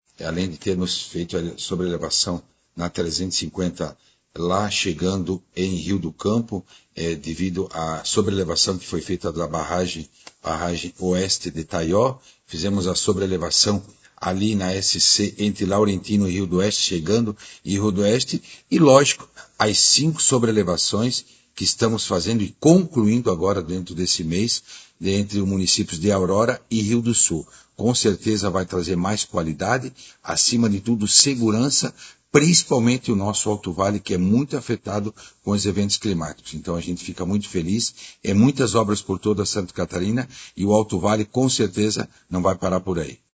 O secretário, Jerry Comper comentou sobre as obras na região que devem continuar: